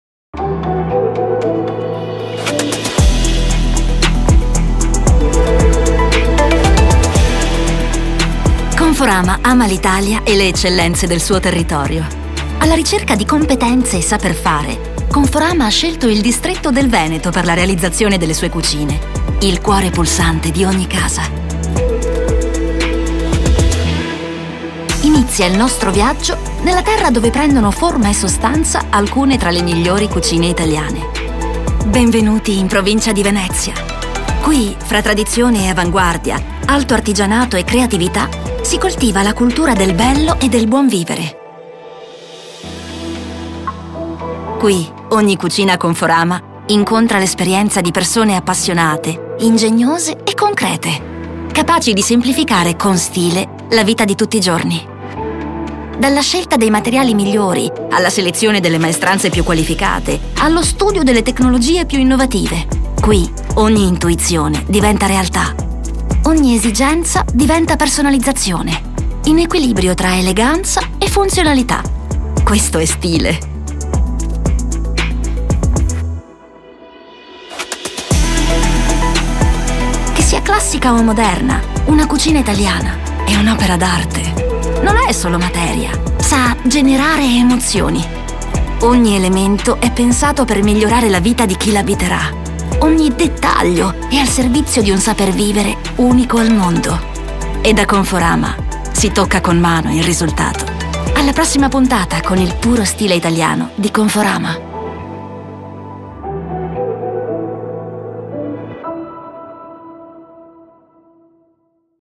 Demo
Corporate